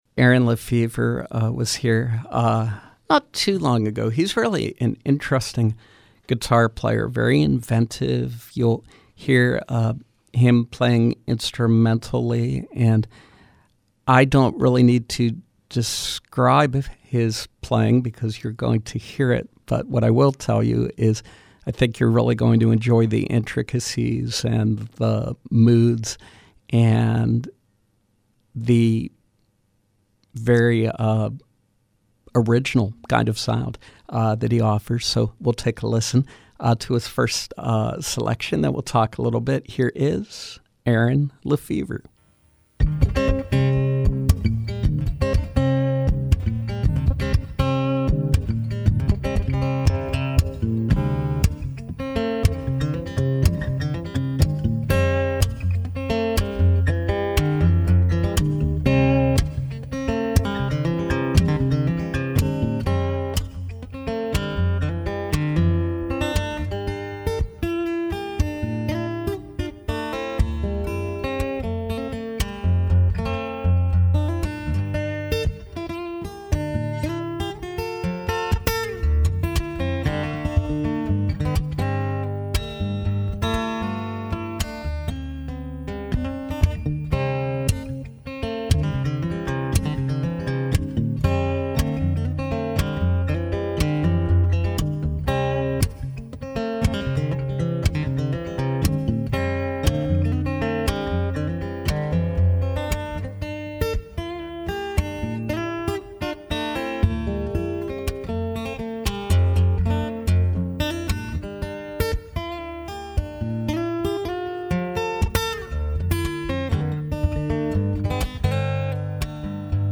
Acoustic music
folk-fusion instrumentalist